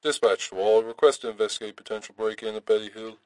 Tag: 喋喋不休 警察 收音机